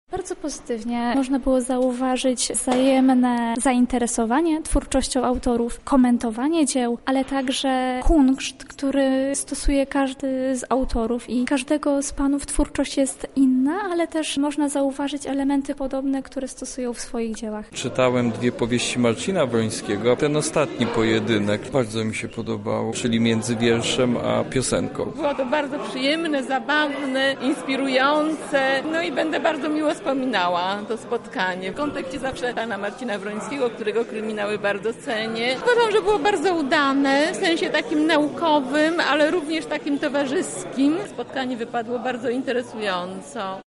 To wydarzenie w ramach KULowskich Spotkań Literackich.